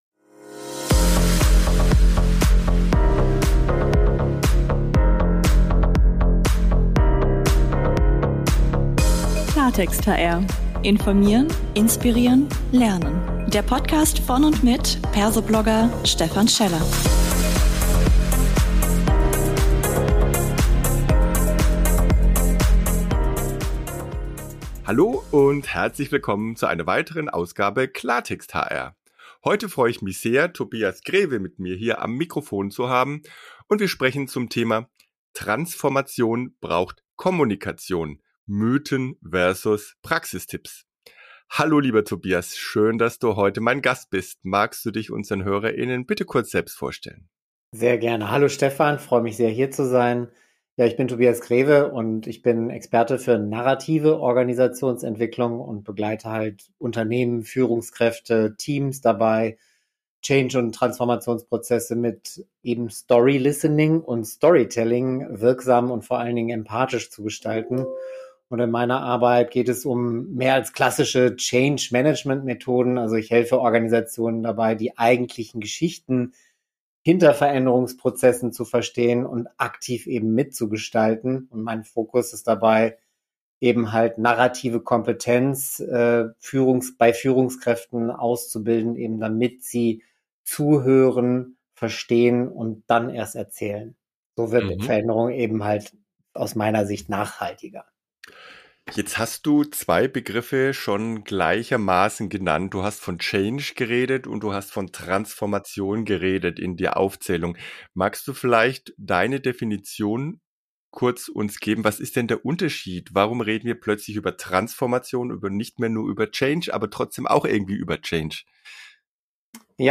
Ein spannender Talk als 15-Minuten-Impuls.